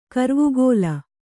♪ karvugōla